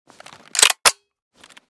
wa2000_unjam.ogg